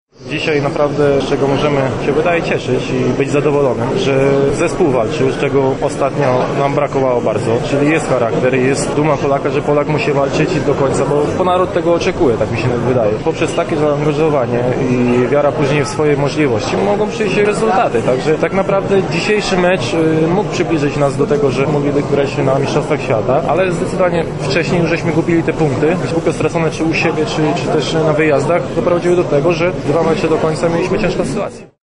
Do kadry Polski, po czterech latach przerwy, powrócił Mariusz Lewandowski. – Możemy być zadowoleni, bo zespół walczył, czego brakowało w ostatnich spotkaniach. Czyli, charakter jest –  mówił po meczu w rozmowie z dziennikarzami doświadczony pomocnik:
Mariusz-Lewandowski-mixzona1.mp3